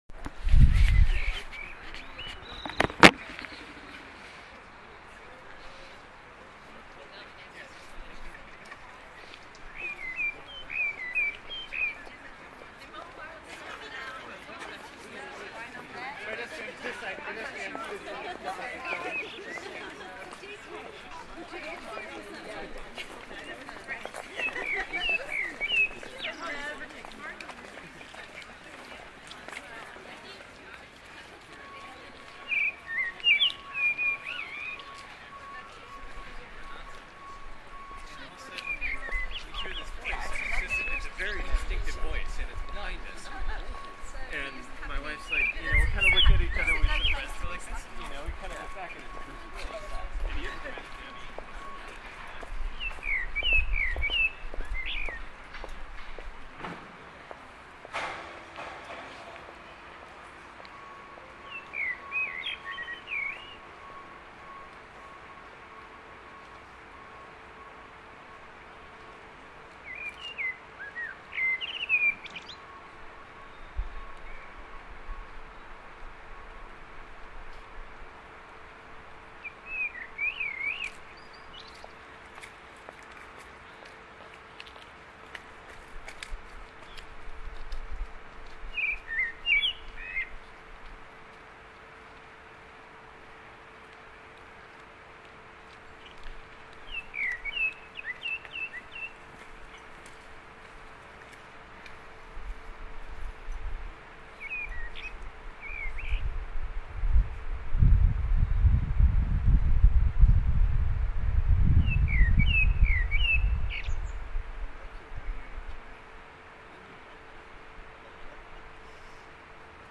Birdsong on the Thames
I found this bird singing tonight as I walked home